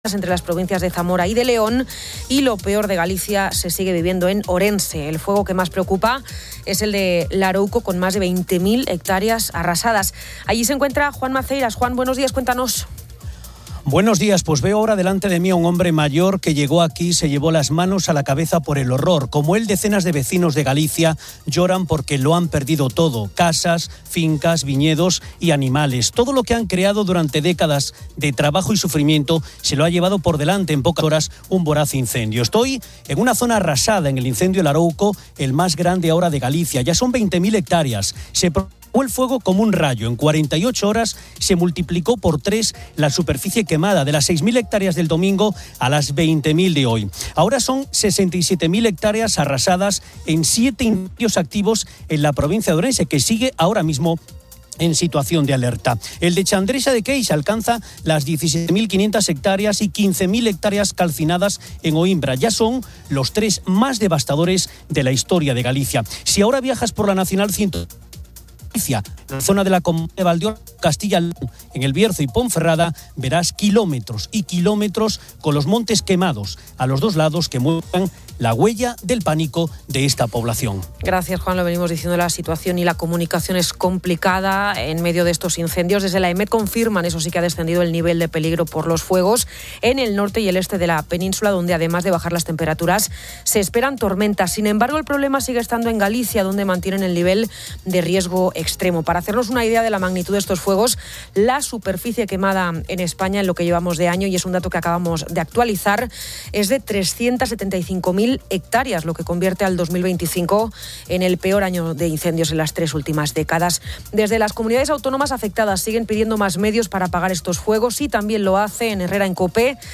Se menciona la pérdida de hogares y bienes, la extensión del fuego a Castilla y León, y la preocupante falta de pilotos para los medios aéreos de extinción. En cuanto al fraude digital, se alerta sobre el aumento de ciberestafas, especialmente el "Bizum inverso", aconsejando a los usuarios verificar las peticiones de dinero. El programa también entrevista